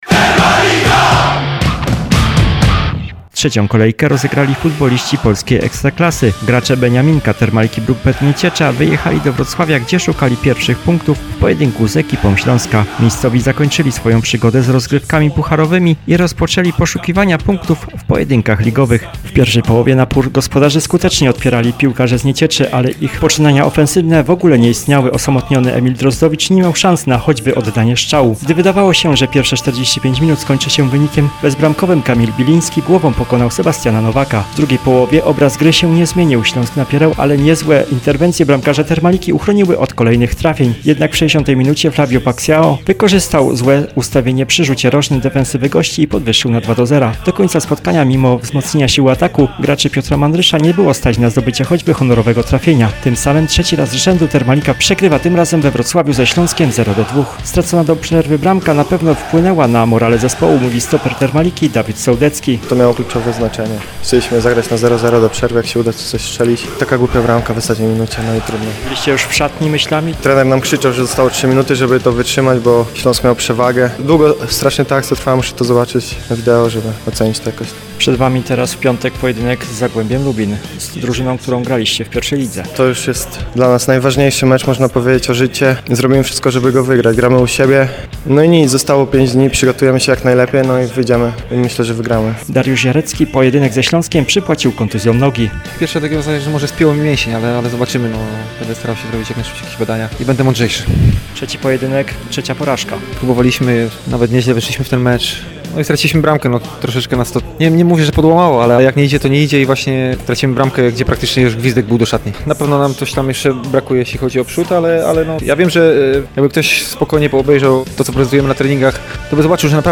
Zapraszamy do posłuchania magazynu poświęconego Termalice Bruk-Betowi Nieciecza. Emitowany jest w radiu RDN w każdy poniedziałek o godzinie 8.15.